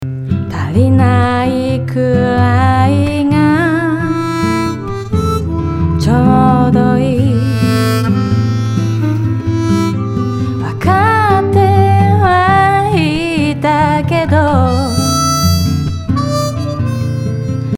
ハーモニカが入った状態のアンサンブルを聴いてみましょう。